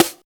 35 SNARE 3.wav